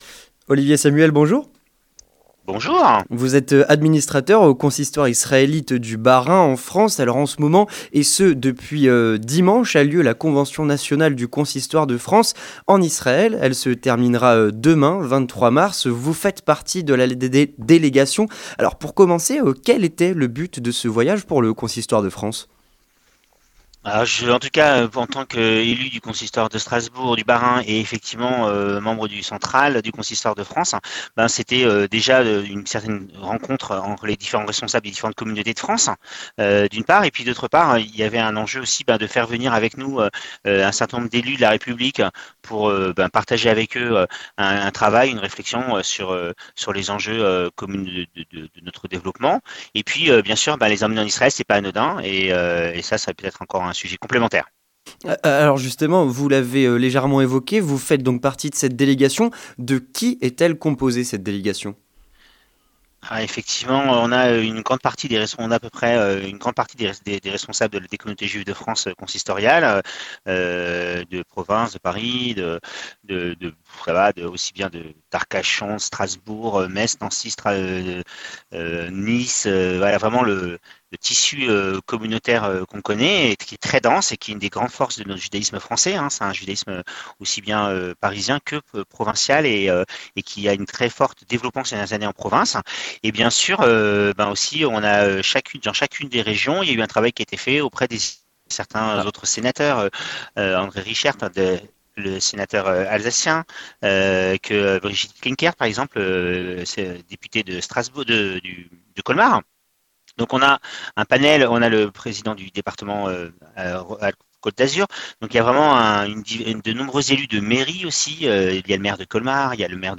Entretien du 18h - Le voyage du consistoire de France